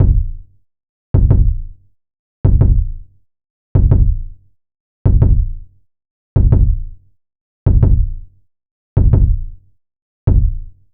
Kick - slide (edit).wav